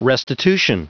Prononciation du mot restitution en anglais (fichier audio)